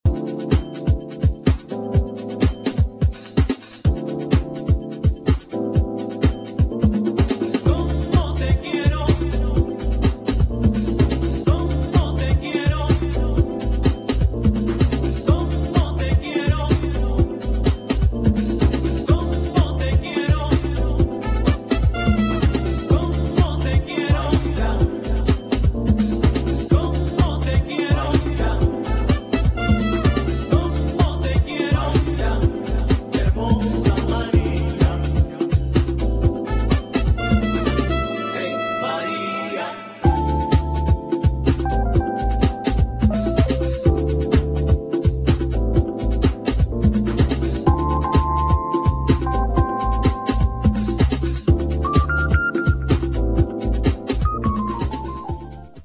[ LATIN JAZZ / DEEP HOUSE ]